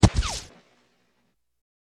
Bullet Hit Ground.wav